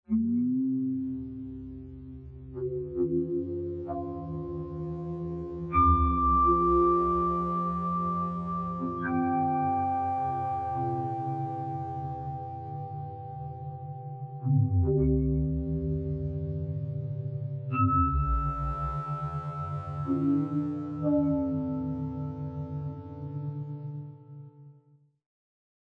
Figure 6. Analysis with smoothed frequencies over 110 frames, and smoothed amplitudes over 10 frames.